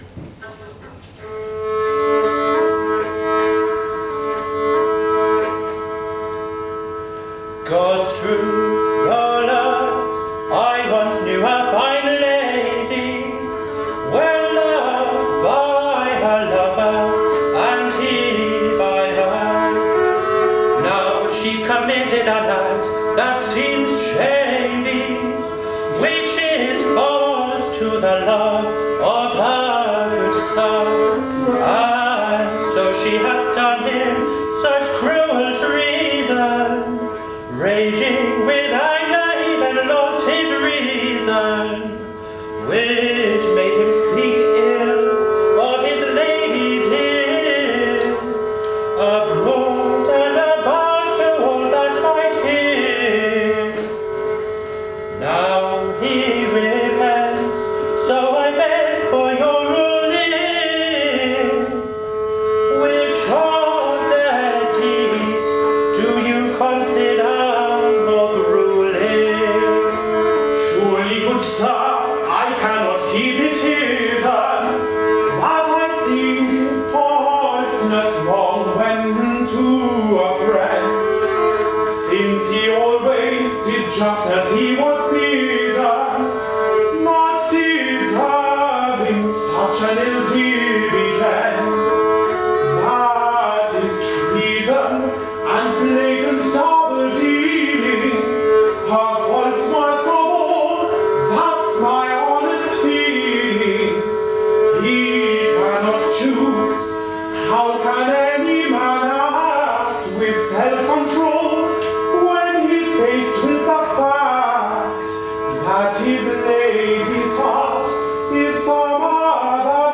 A second post from the performance workshop with graindelavoix, sponsored by the Leverhulme Trust and held at St Hugh’s College, Oxford in March 2017.
jp4-english.wav